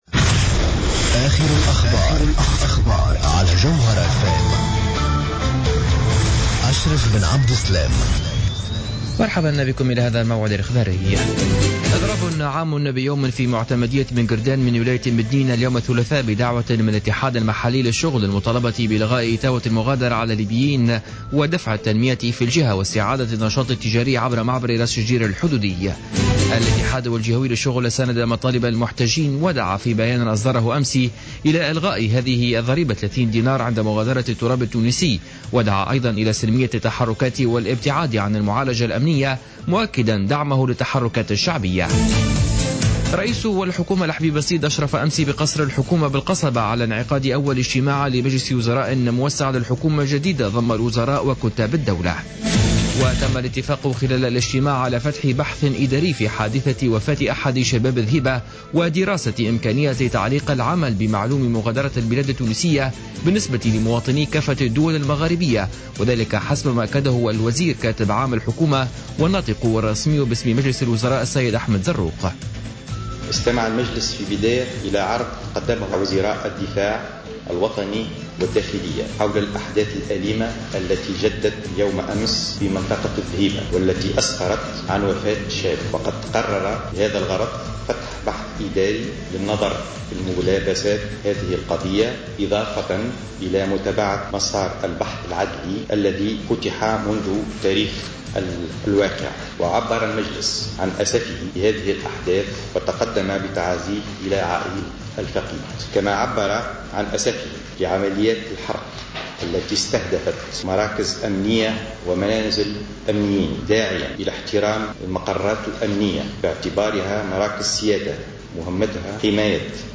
نشرة أخبار منتصف الليل ليوم الثلاثاء 10 فيفري 2015